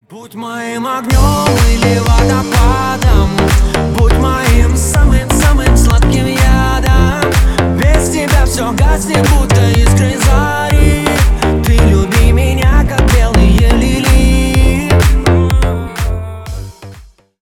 поп , ремиксы